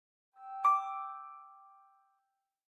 Звуки Gmail уведомлений скачать - Zvukitop